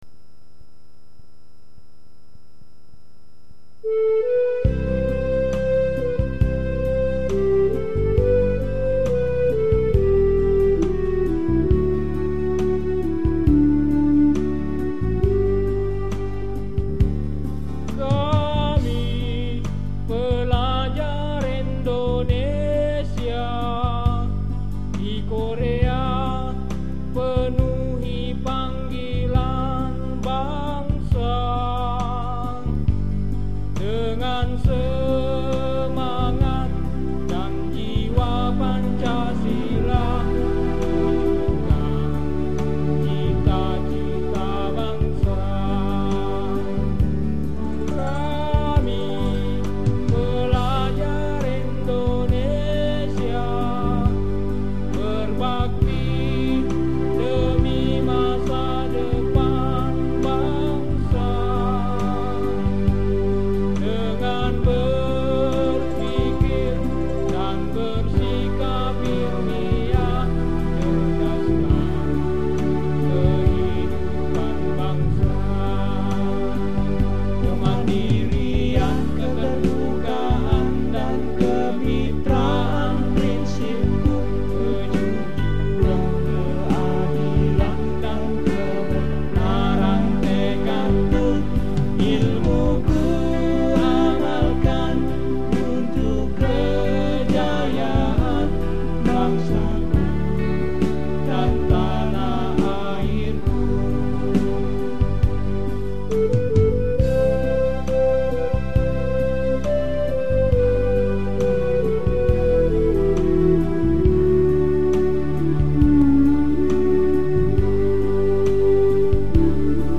HYMNE
HymnePerpika.mp3